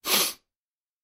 Звуки шмыганья носом
Звук забитой соплями ноздри